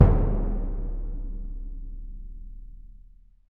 Index of /90_sSampleCDs/Roland LCDP03 Orchestral Perc/PRC_Orch Bs Drum/PRC_Orch BD Roll